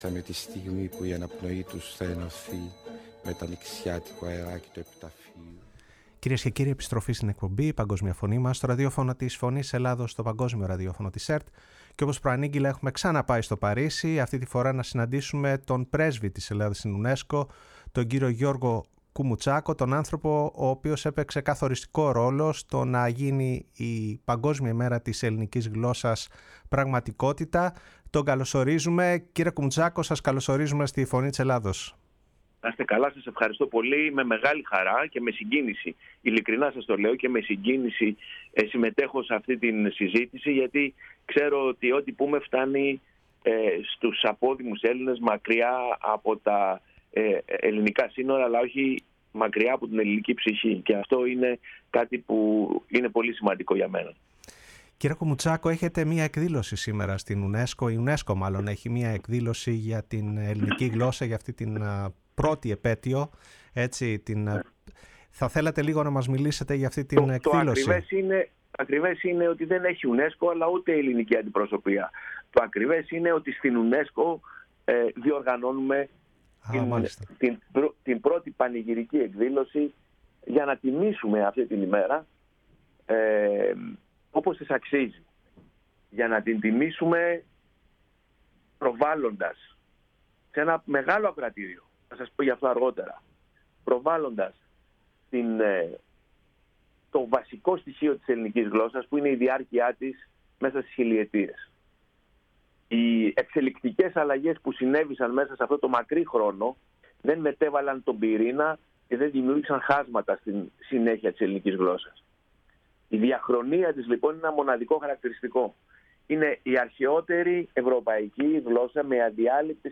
μιλώντας στη Φωνή της Ελλάδας και στην εκπομπή “Η Παγκόσμια Φωνή μας”